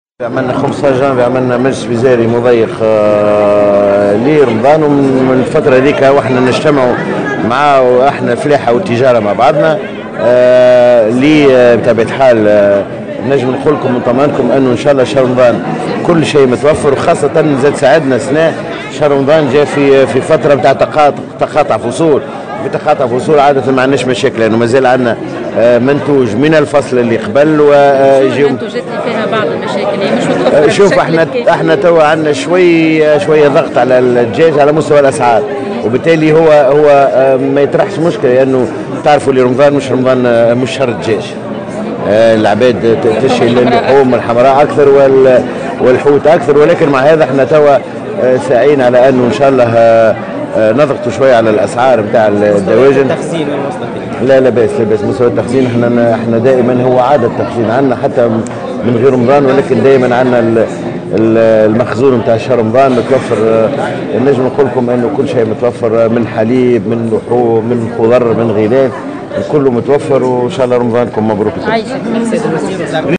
وجاءت تصريحاته على هامش افتتاح المقر الجديد للهيئة الوطنية لمكافحة الفساد.